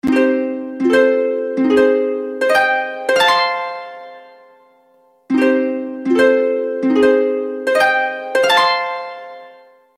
Рингтоны Без Слов